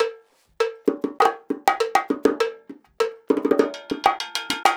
100 BONGO4.wav